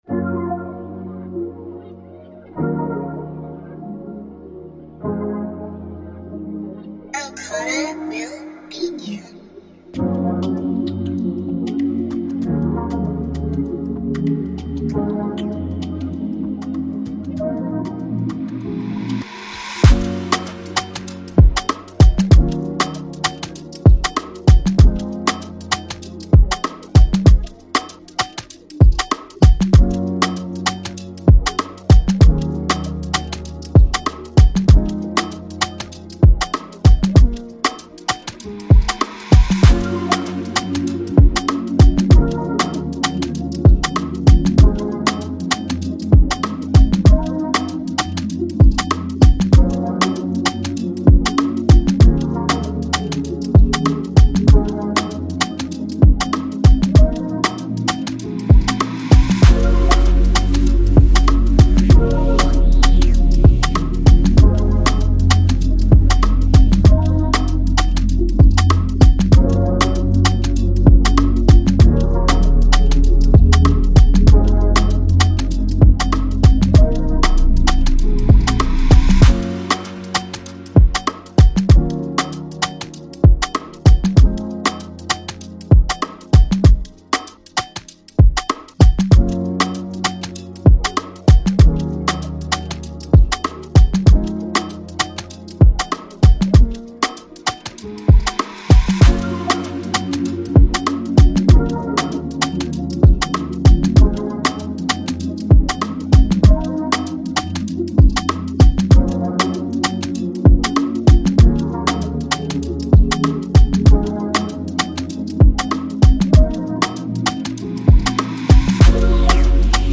it’s made with cool sounds and snares.